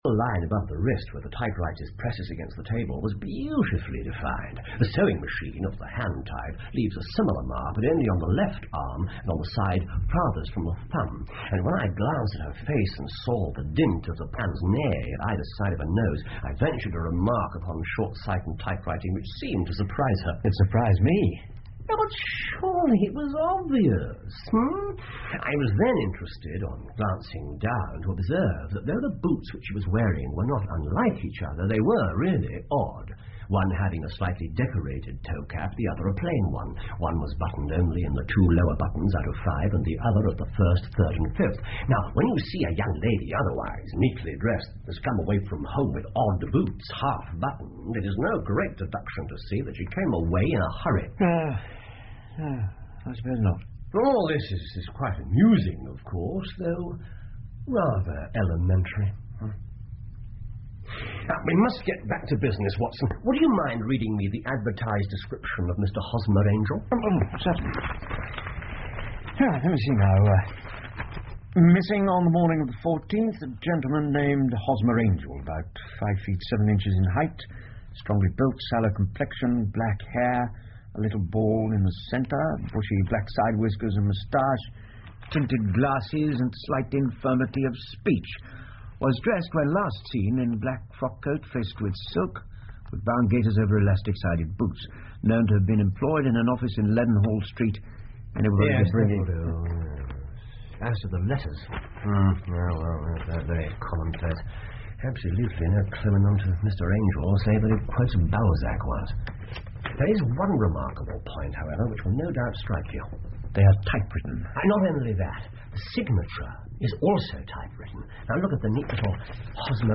福尔摩斯广播剧 A Case Of Identity 6 听力文件下载—在线英语听力室